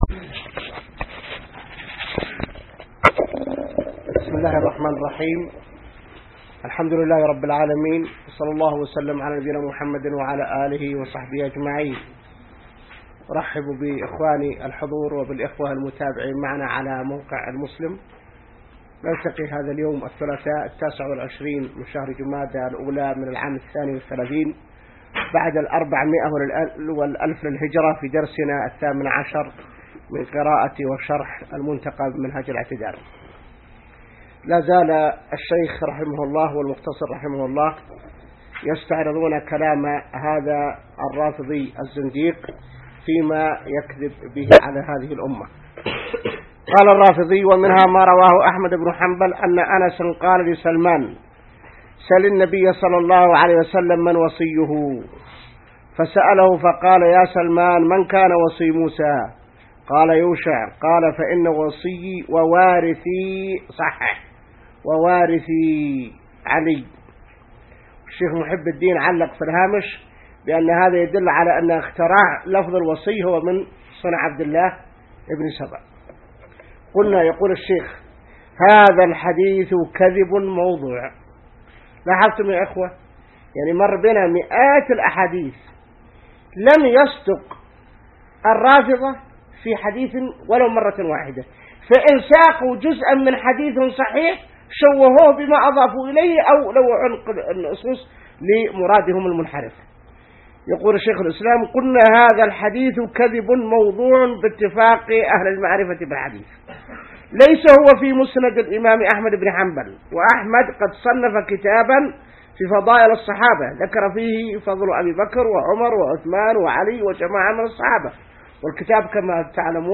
الدرس 18 من شرح كتاب المنتقى | موقع المسلم